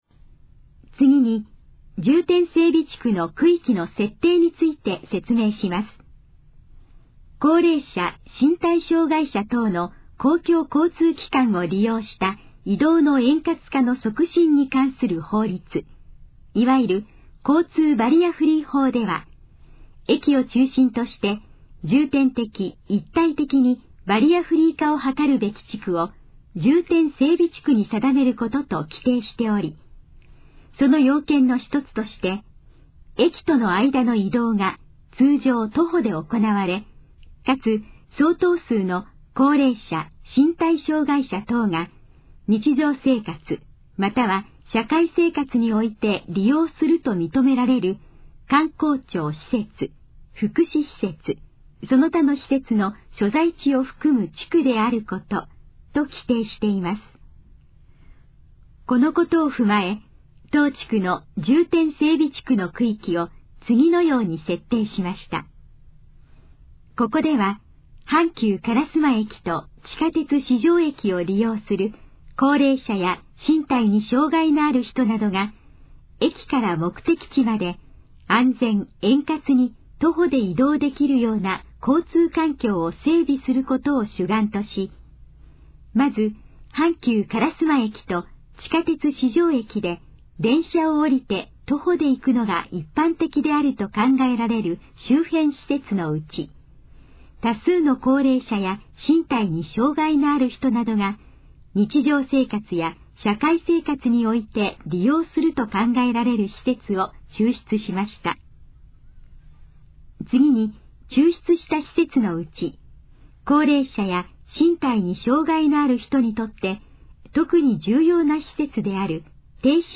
このページの要約を音声で読み上げます。
ナレーション再生 約386KB